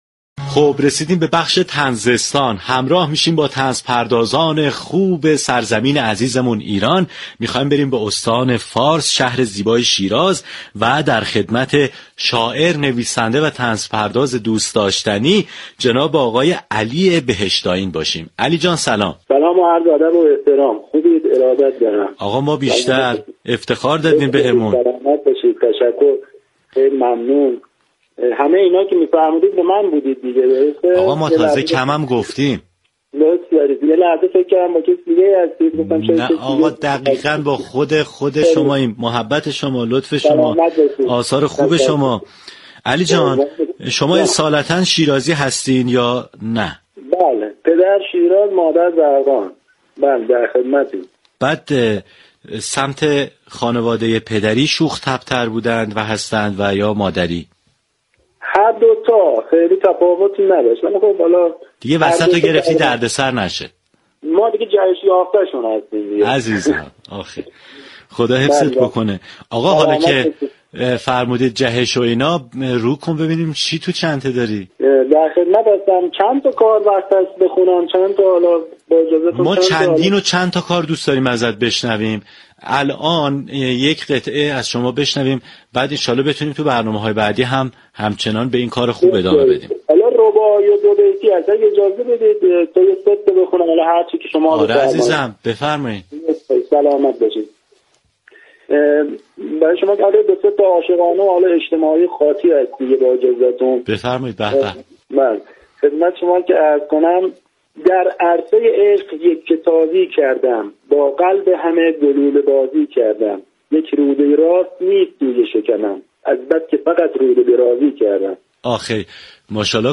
گفتگوی رادیو صبا